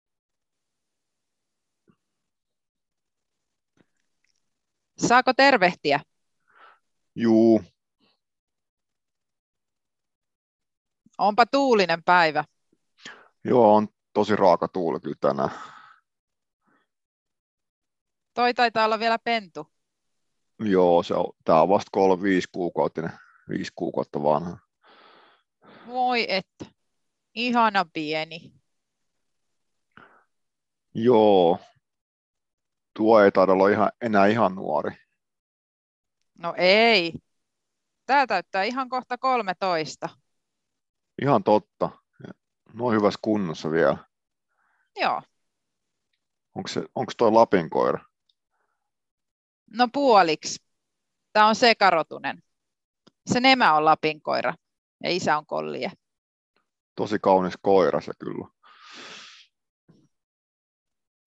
dialogi-3.mp3